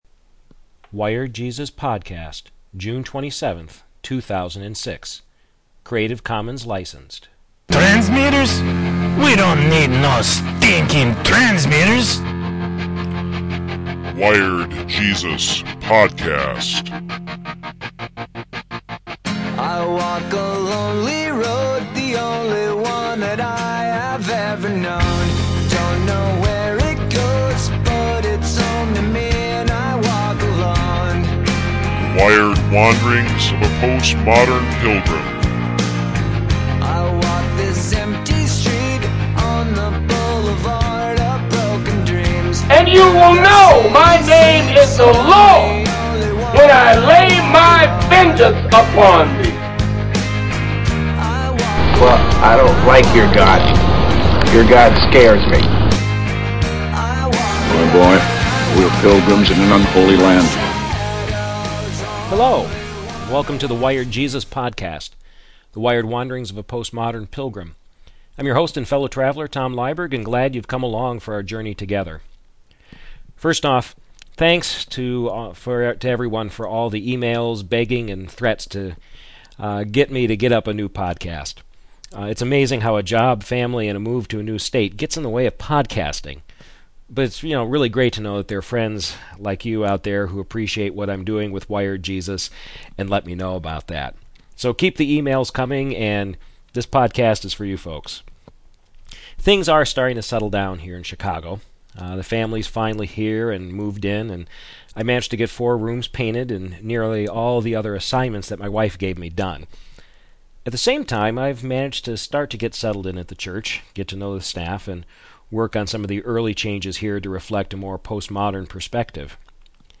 Here we go, reflections on the theological/geographical reality of living betwixt (I’ve always wanted to use that word) The Billy Graham Center at Wheaton College and the megachurch Willow Creek. Live from the banks of the Mississippi where I am away at camp, Wired Jesus is back.